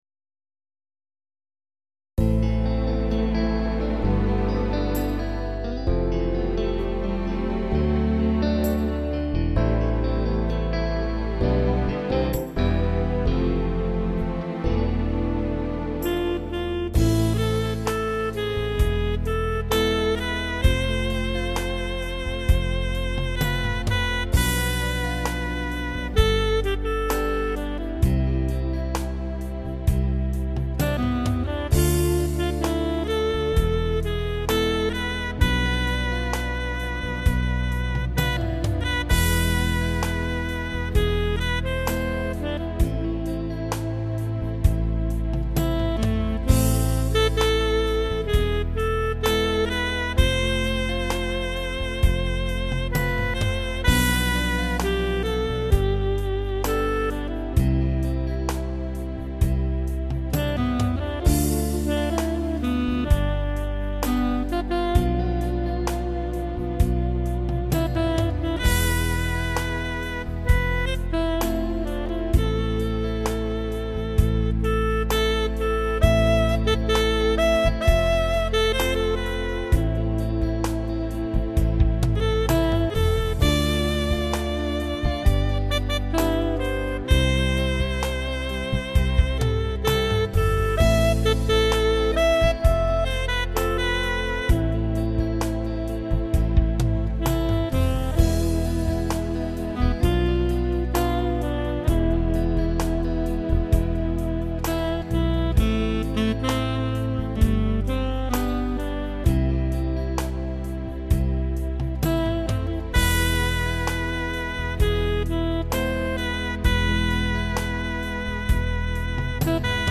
Keyboard
Ballad version:
NiemRiengLenHoi_Ballad-1.mp3